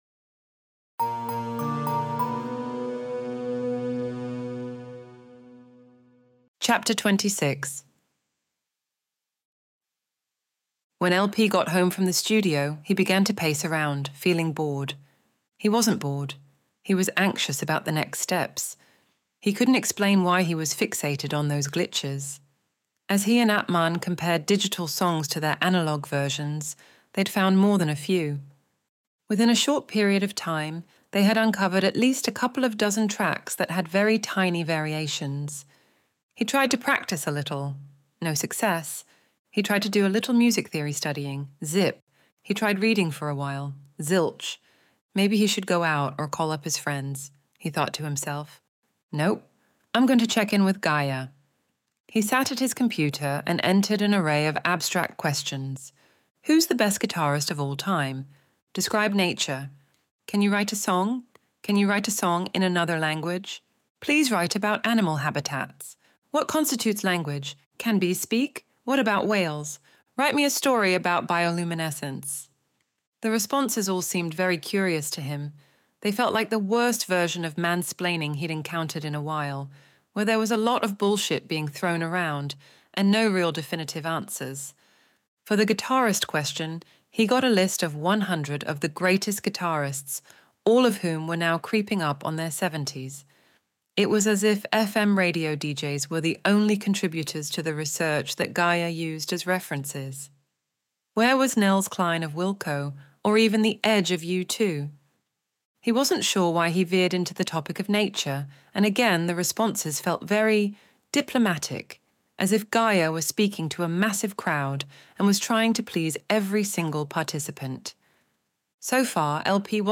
Extinction Event Audiobook Chapter 26